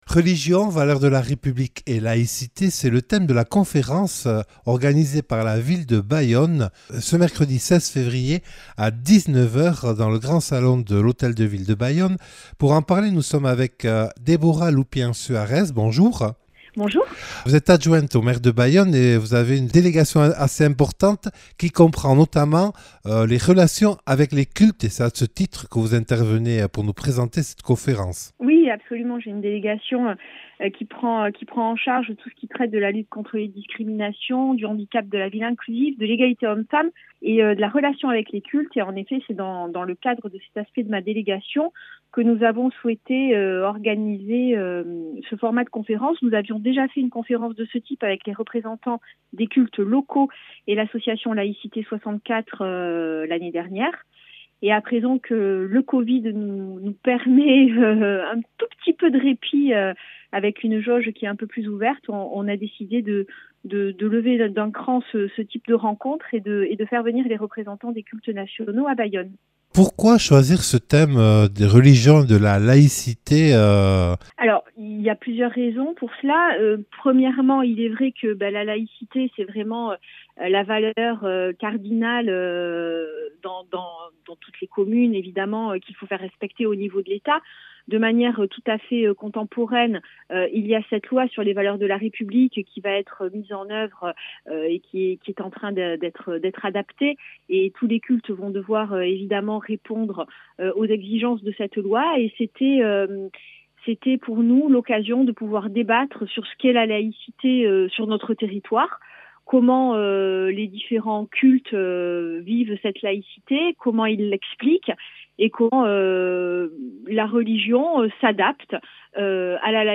Déborah Loupien-Suarès, adjoint au Maire de Bayonne nous présente cette conférence organisée par la ville de Bayonne et qui aura lieu au Grand Salon de la Mairie, le mercredi 16 février à 19h, en présence des intervenants suivants :